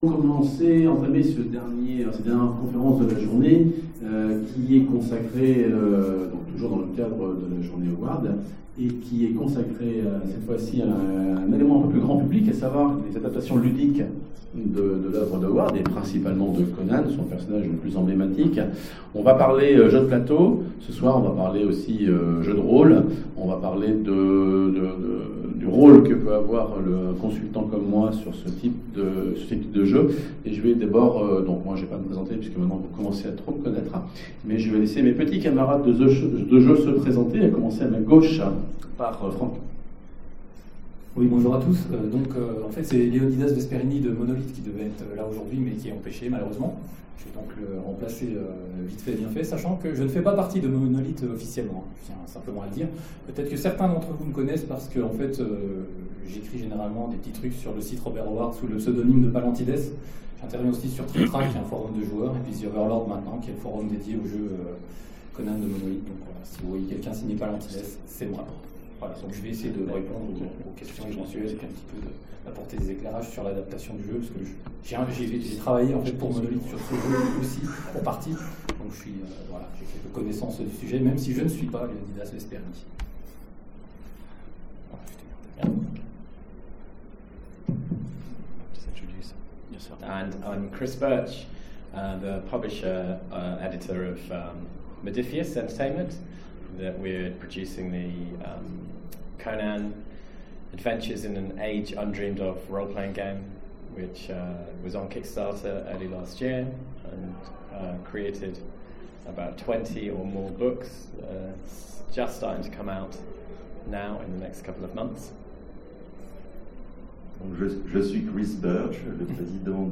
Conférence FMI 2017 : Le développement de Conan chez Modiphius